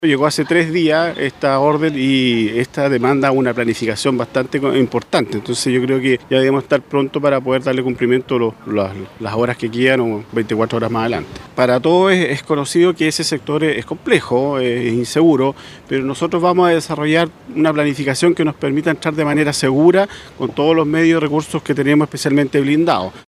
El jefe (s) de la IX Zona de Carabineros, General Cristian Mansilla, confirmó que preparan un plan especial, considerando el riesgo que significa intentar ingresar a Temucuicui.